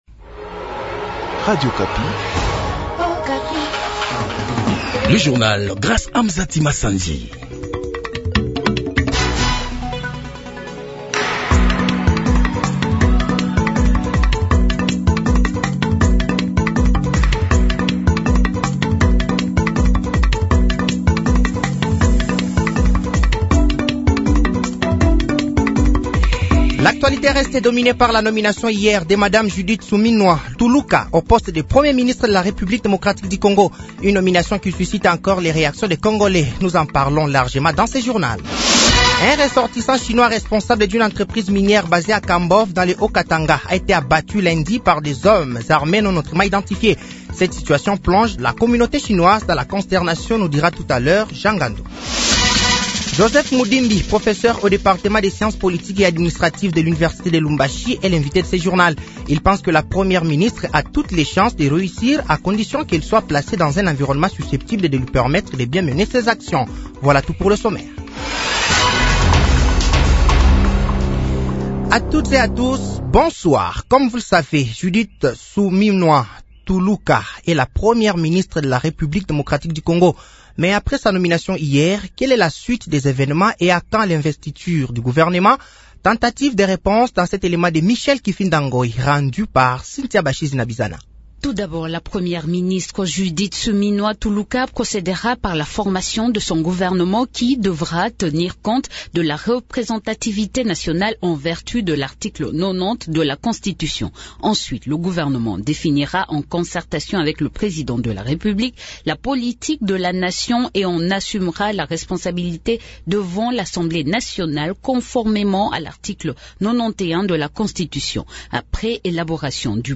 Journal français de 18h de ce mardi 02 avril 2024